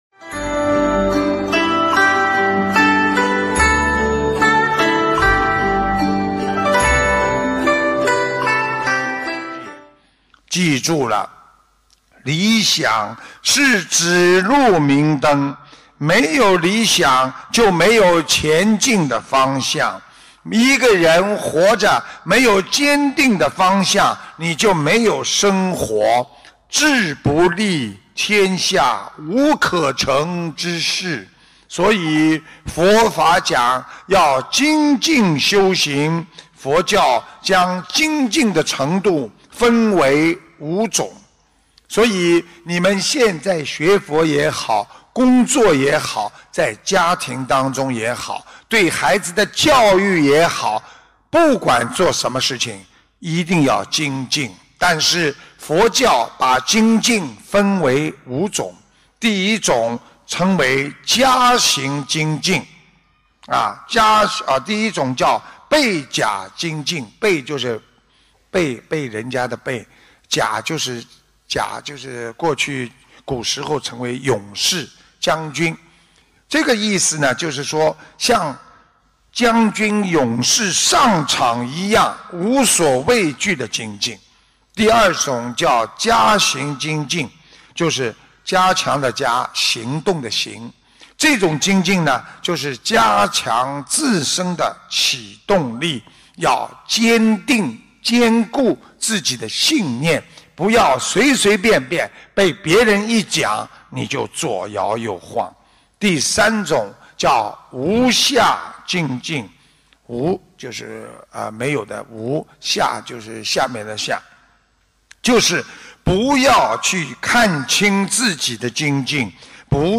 悉尼玄艺综述大型解答会！